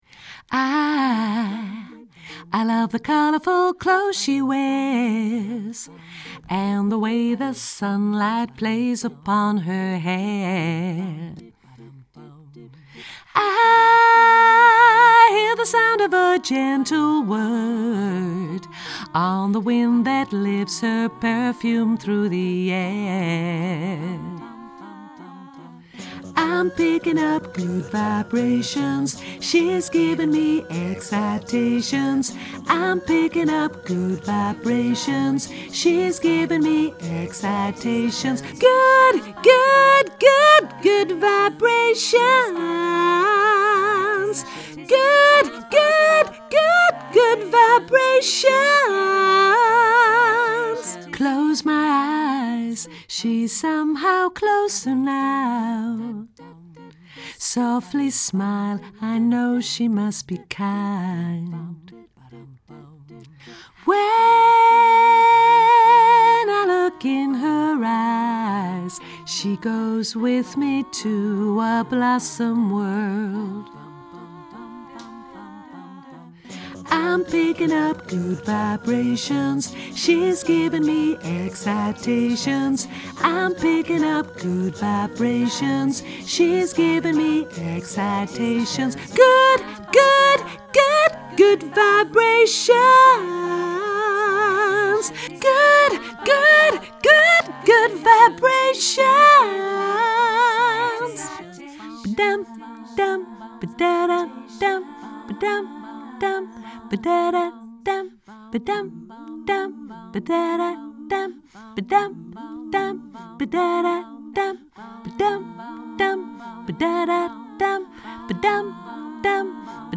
mezzo